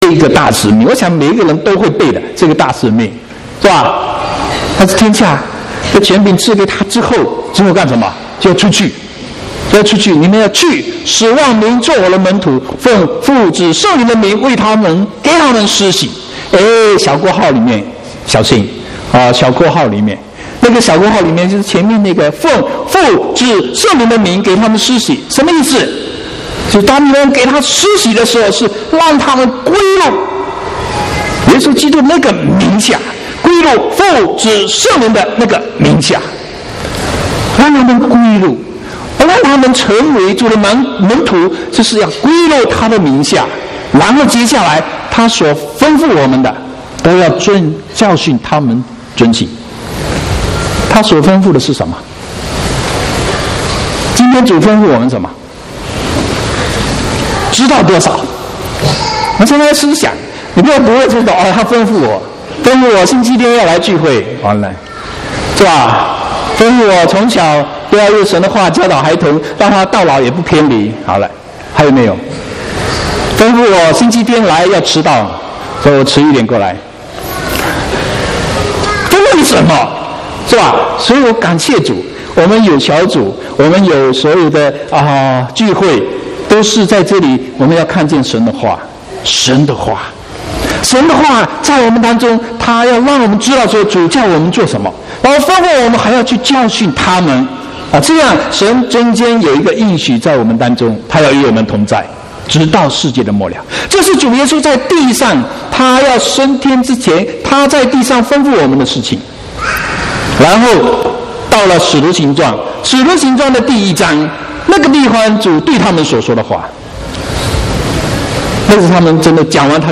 7/8/2016國語堂講道